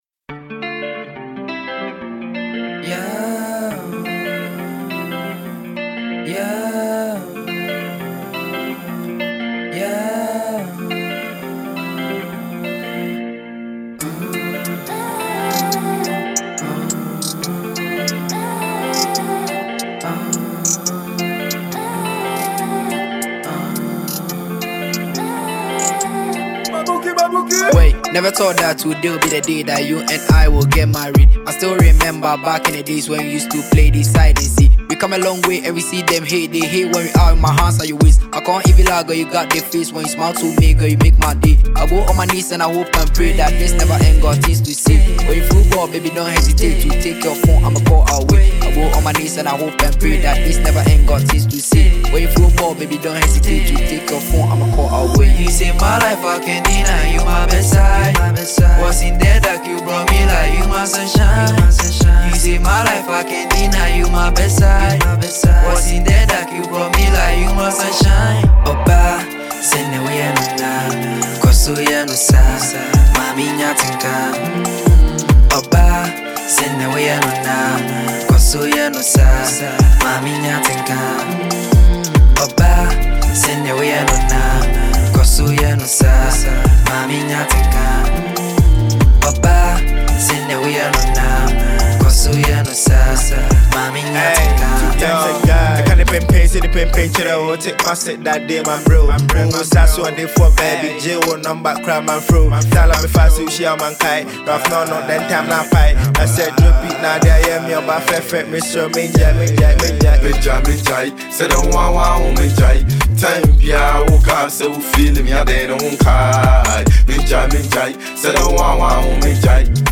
Ghana Music Music
Ghanaian Kumerica Rapper